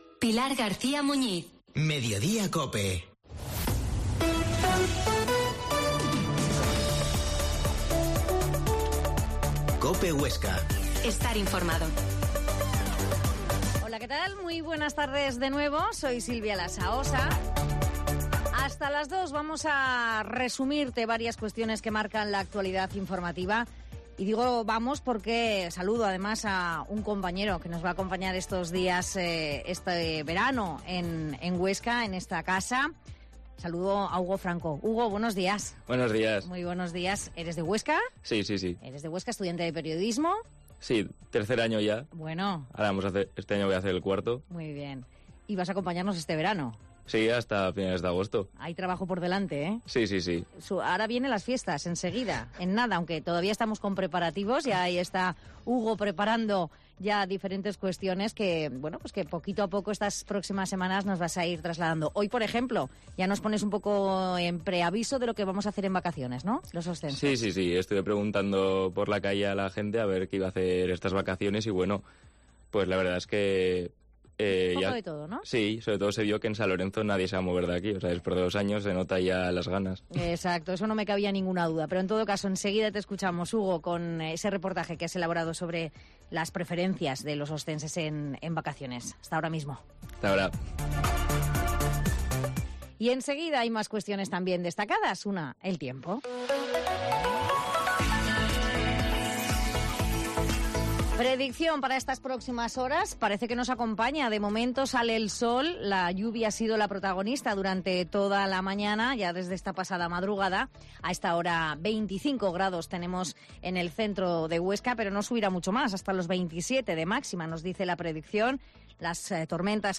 AUDIO: Última hora de la actualidad, hablamos con los oscenses para que nos cuenten cómo van a ser sus vacaciones